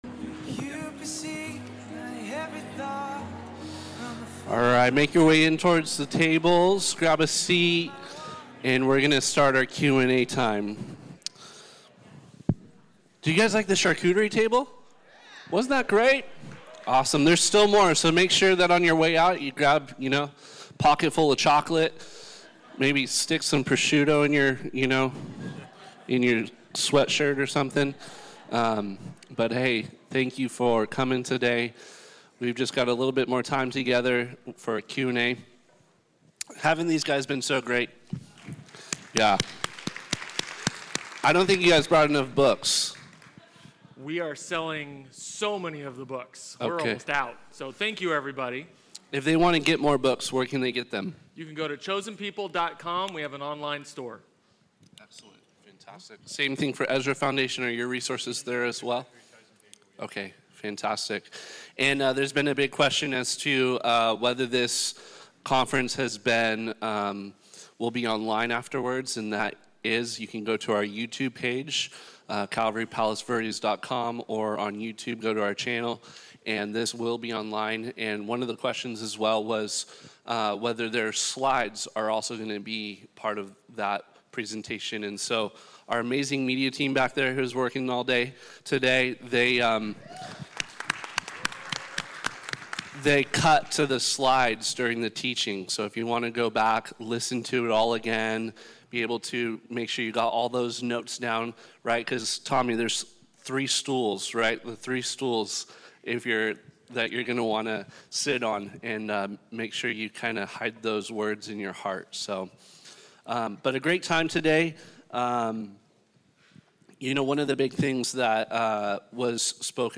Israel Conference 2024 (Q+A)